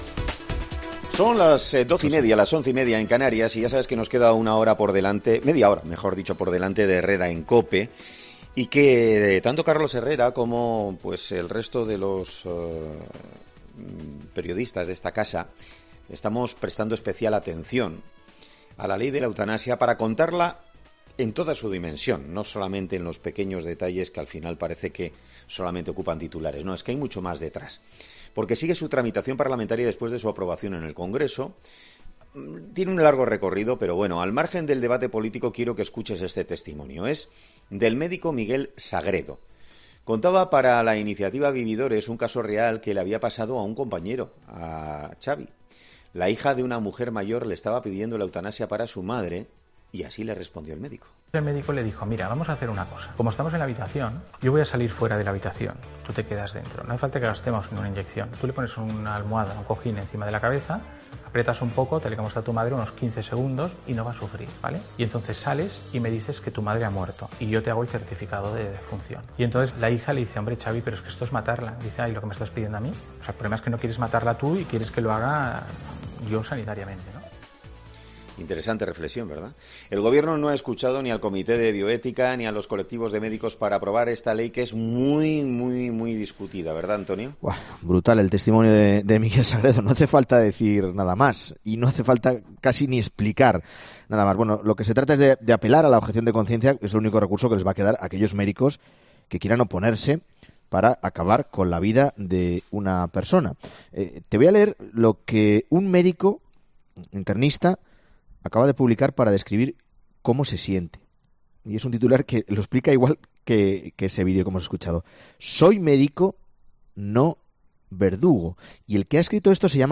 Médico internista, en COPE: “Queremos eliminar el sufrimiento de las personas, no a la persona que sufre”